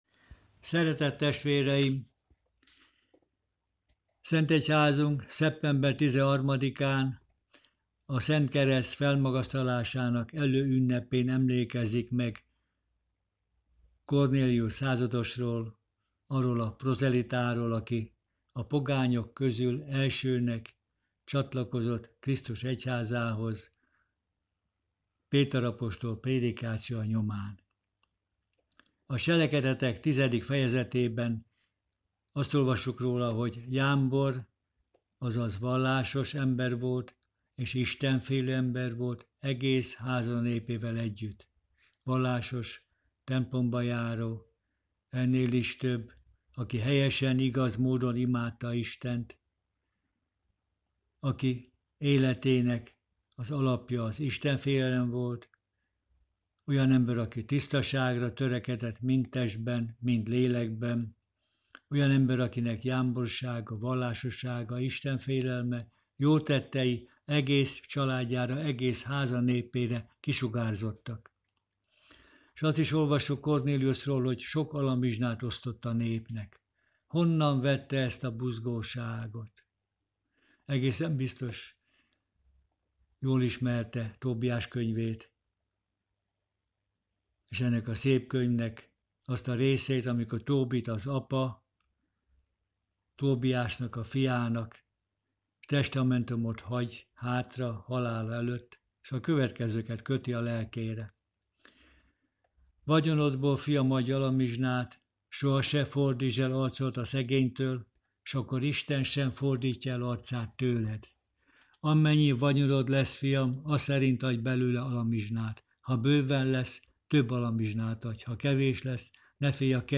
Pár vigasztaló szó